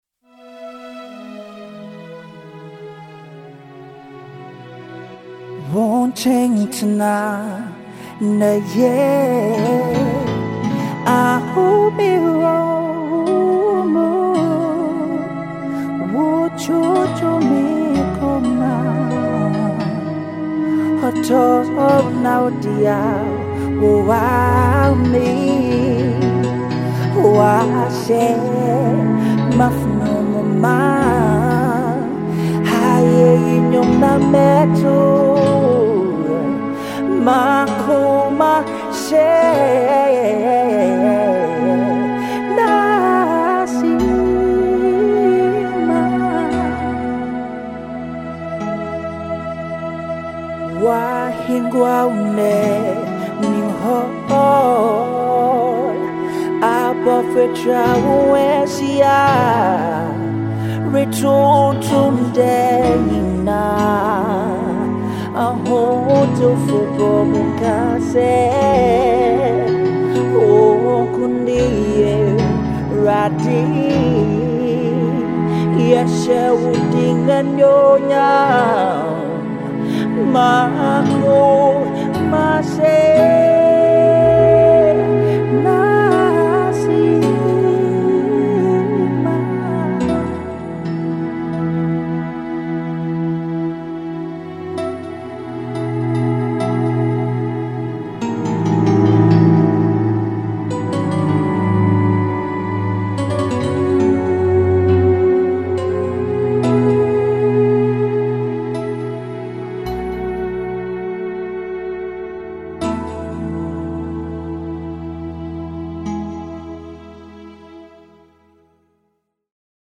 Budding gospel singer and vocalist